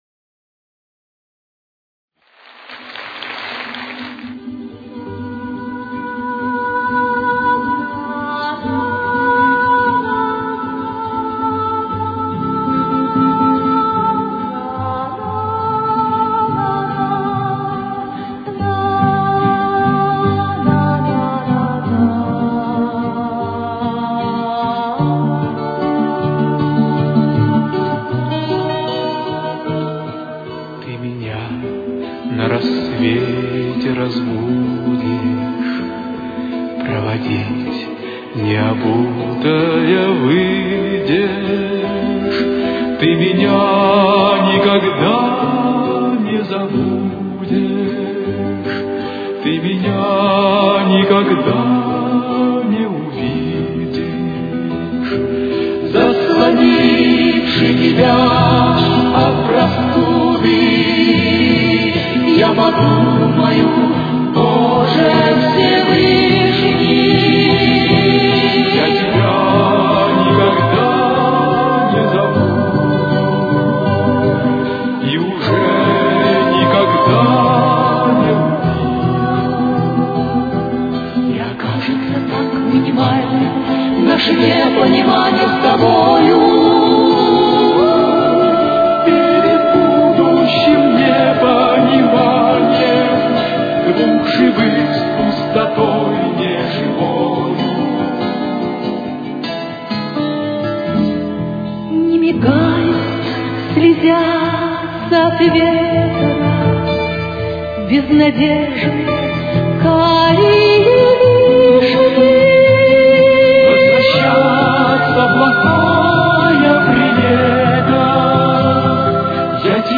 с очень низким качеством (16 – 32 кБит/с)
Тональность: Ми минор. Темп: 77.